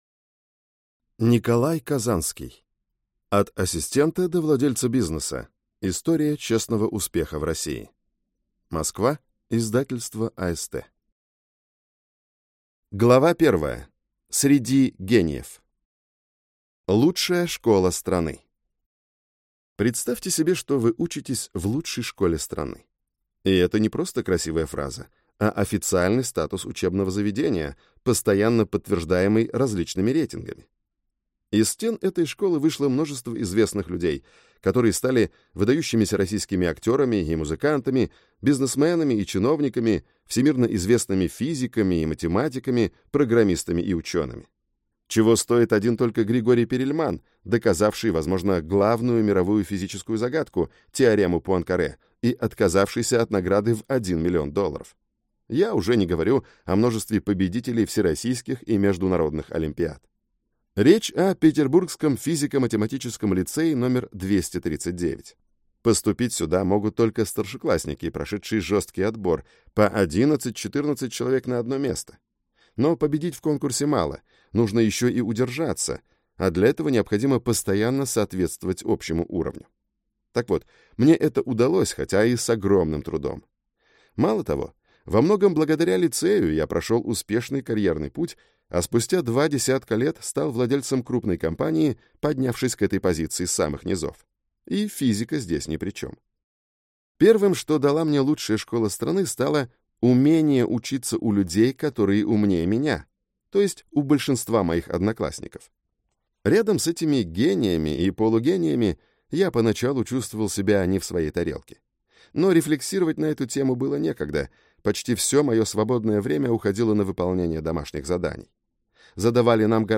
Аудиокнига От ассистента до владельца бизнеса | Библиотека аудиокниг